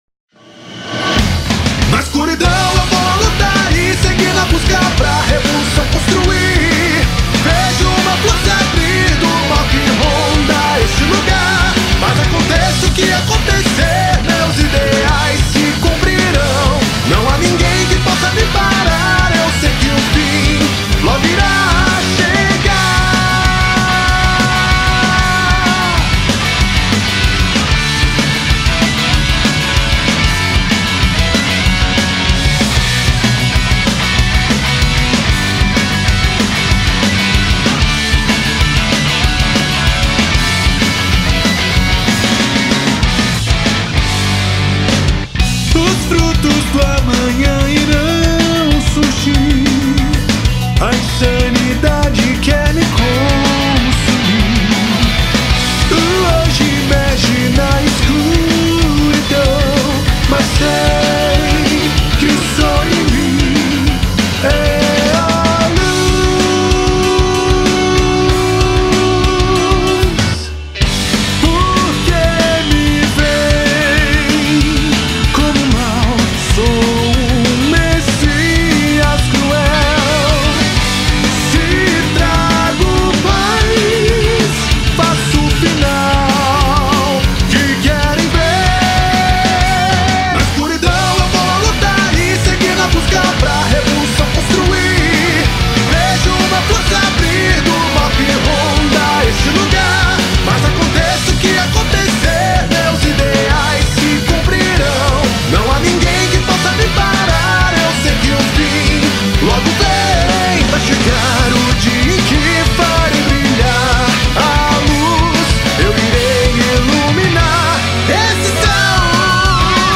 2025-02-23 15:53:53 Gênero: Rap Views